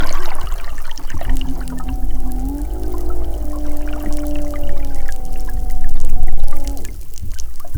Em janeiro deste ano, em uma expedição de pesquisa rotineira do Jubarte.lab/Projeto Amigos da Jubarte para monitoramento de cetáceos da costa da Grande Vitória, o grupo de pesquisadores registrou através do monitoramento de bioacústica o canto de uma baleia-jubarte.
Um microfone subaquático consegue captar esses sons de diferentes frequências.
As baleias se comunicam em uma frequência mais baixa e, em grande parte da frequência que elas se comunicam, é possível escutar com o ouvido humano quando mergulhamos a mais de 1 metro de profundidade.
O canto foi registrado apenas em machos de baleia-jubarte, o motivo ainda não se sabe, há hipóteses que seja para atrair parceiras, comunicação ou sucesso reprodutivo dos machos da espécie.